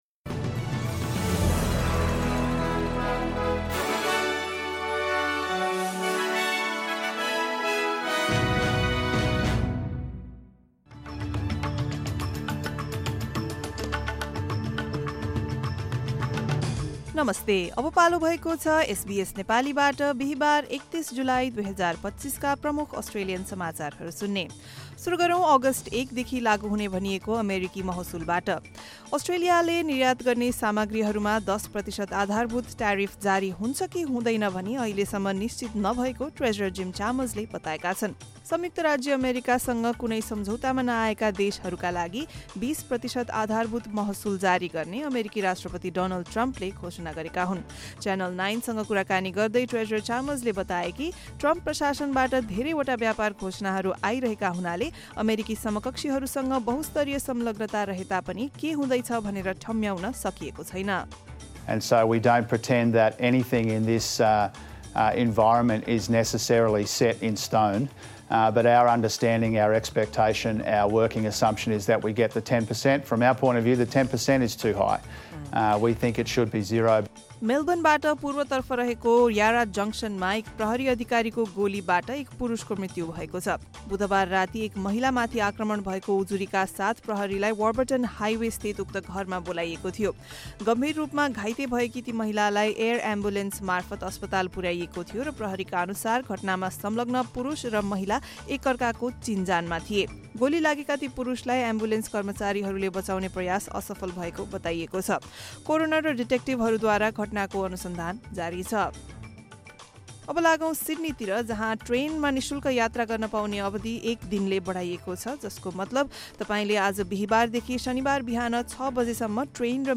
SBS Nepali Australian News Headlines: Thursday, 31 July 2025